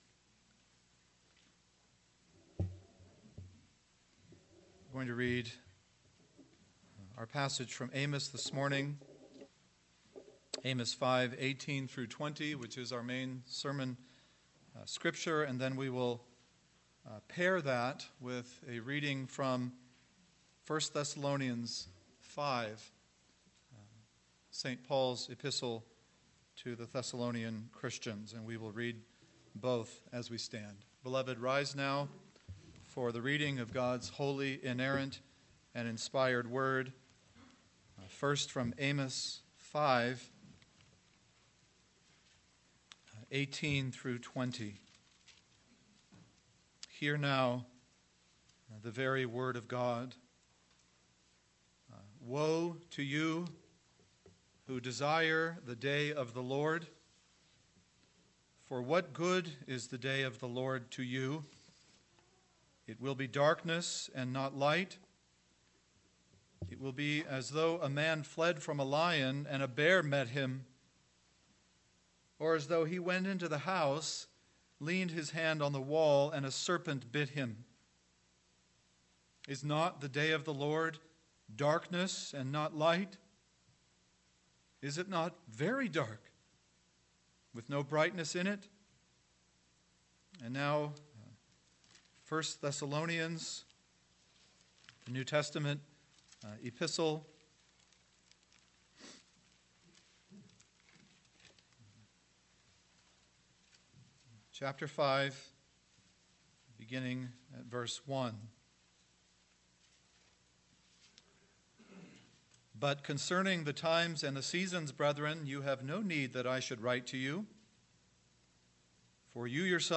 AM Sermon – 09/30/2018 – Amos 5:18-20 – Do You Make Light of the Day of the Lord?